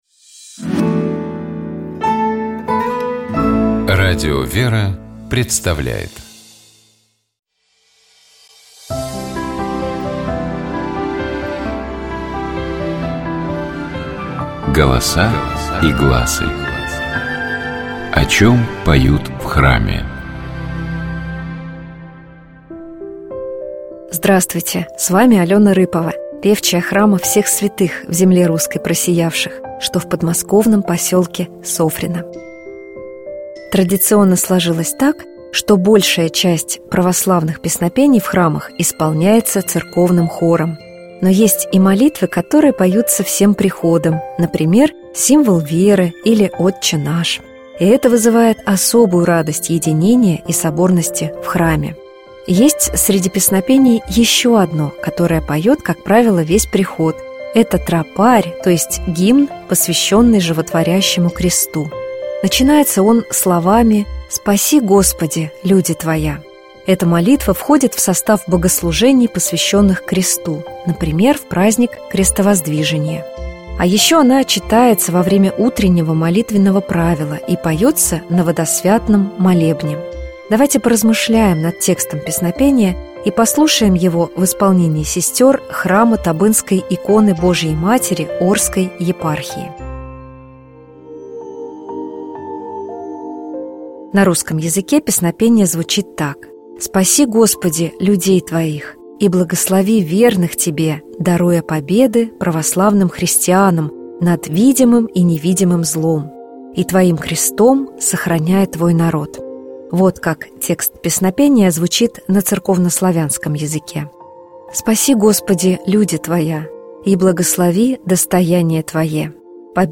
Давайте поразмышляем над текстом песнопения и послушаем его в исполнении сестёр храма Табынской иконы Божией Матери Орской епархии.